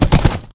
sounds / mob / horse / gallop4.ogg
gallop4.ogg